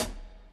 Hat
Original creative-commons licensed sounds for DJ's and music producers, recorded with high quality studio microphones.
Foot Cymbal D# Key 07.wav
foot-cymbal-d-sharp-key-07-coK.wav